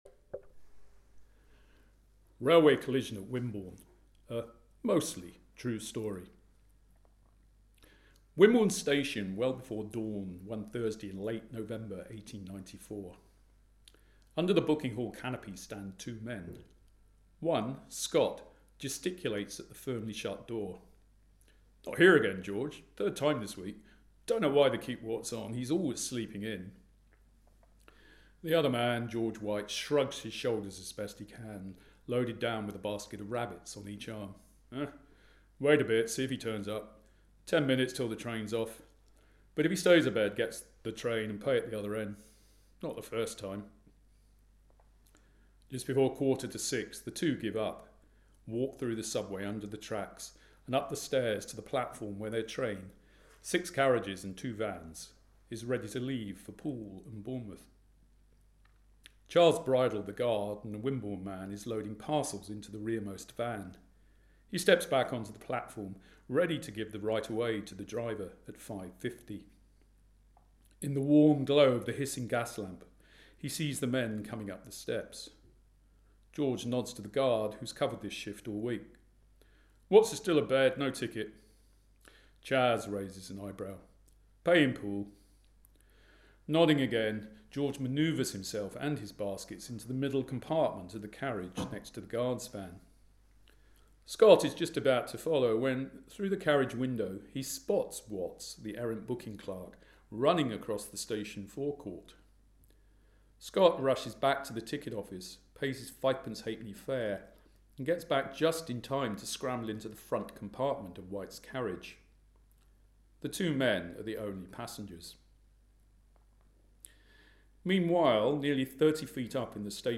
A later audio recording of the story is available along with a transcript, originally published in the South Western Circular (April 2025).